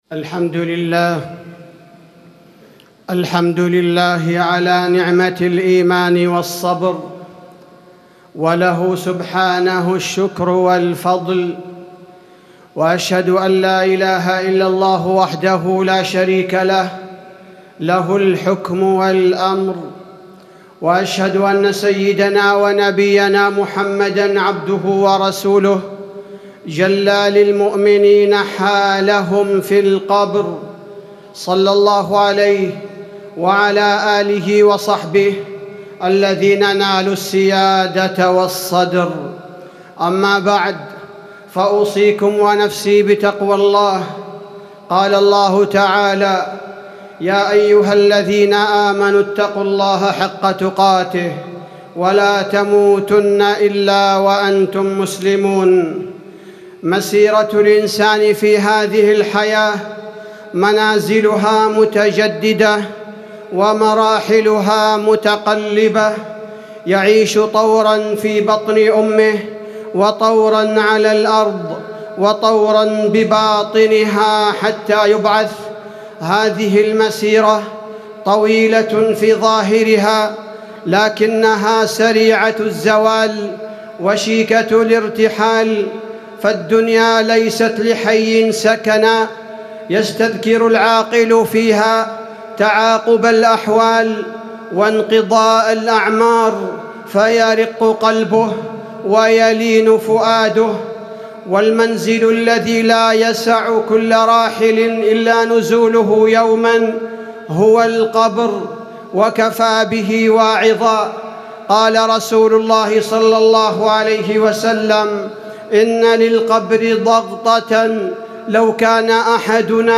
تاريخ النشر ٦ رجب ١٤٣٩ هـ المكان: المسجد النبوي الشيخ: فضيلة الشيخ عبدالباري الثبيتي فضيلة الشيخ عبدالباري الثبيتي أسباب نعيم القبر وعذابه